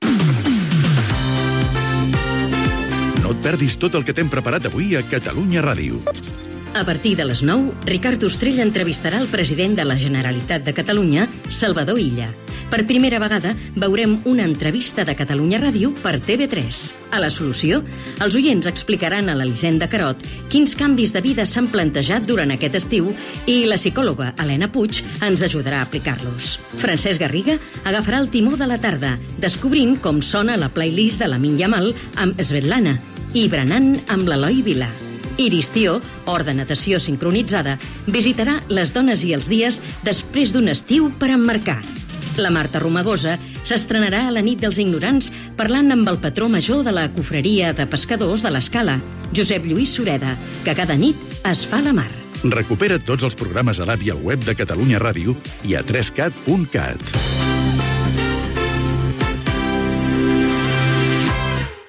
Promoció de la programació de dia 1 de setembre de 2025
FM